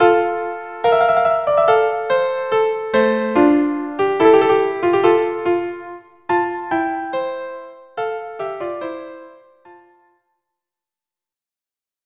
Instrumentation: Violin and Viola
majestic yet brisk march